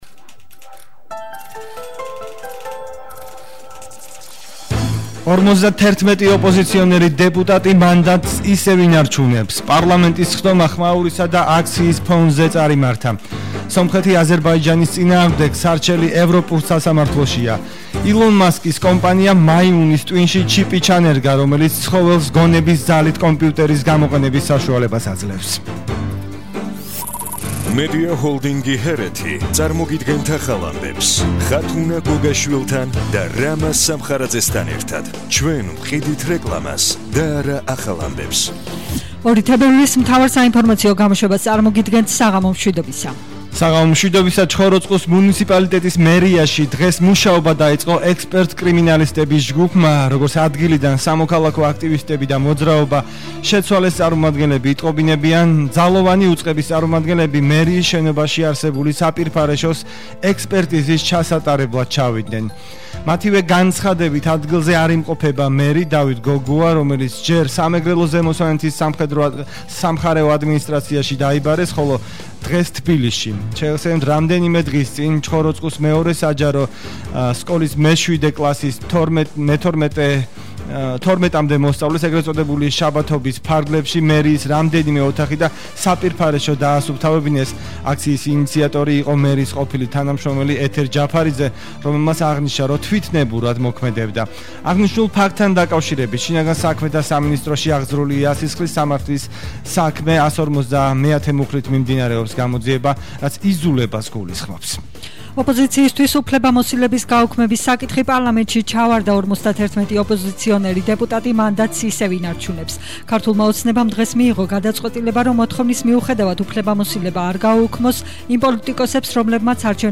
მთავარი საინფორმაციო გამოშვება –02/02/21 - HeretiFM